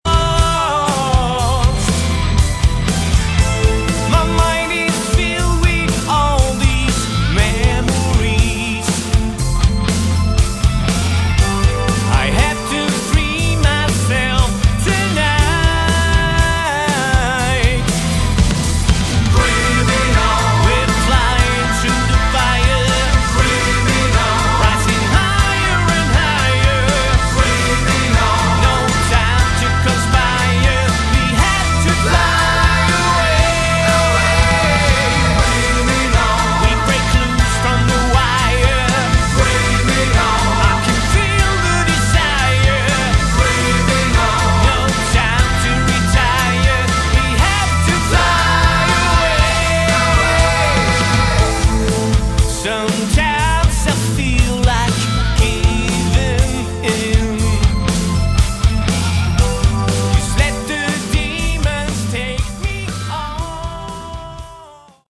Category: Melodic Rock
drums, vst-bass, keyboards and backing vocals
lead and backing vocals
guitars